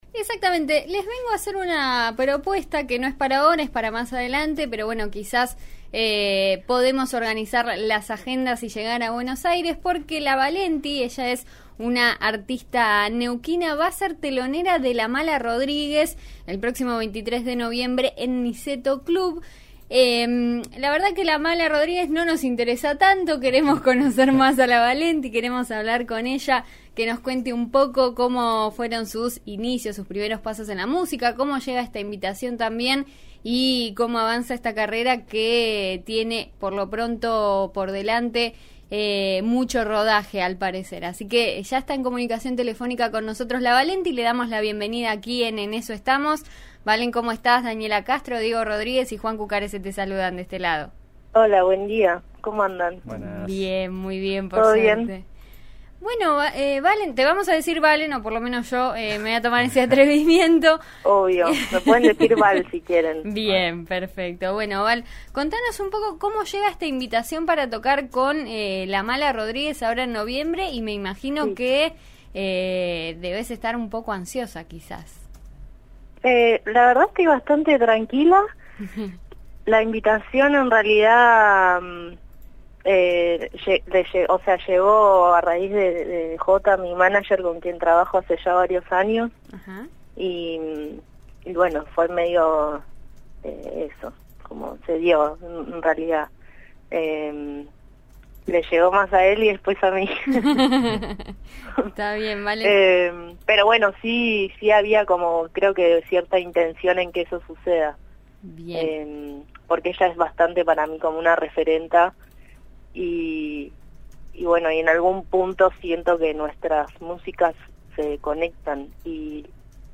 Antes de su próxima gran actuación habló con En eso estamos de RN Radio (105.7 en Roca y 90.9 en Neuquén).